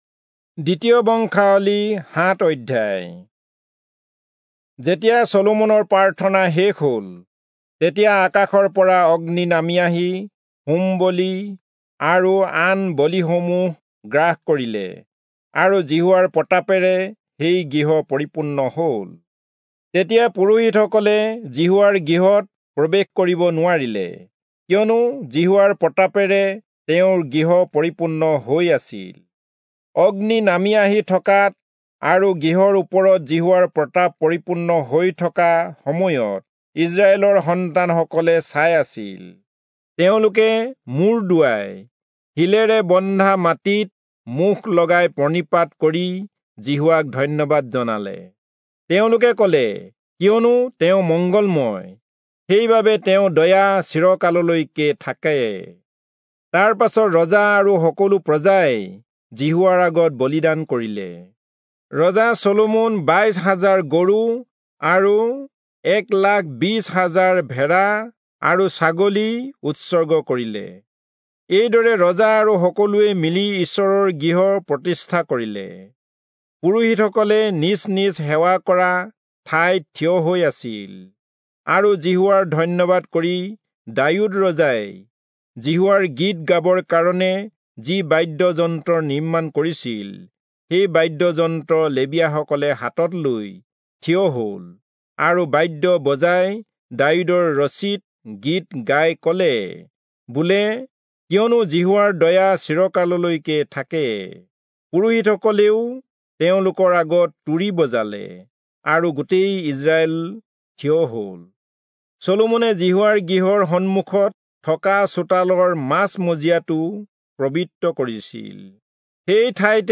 Assamese Audio Bible - 2-Chronicles 1 in Alep bible version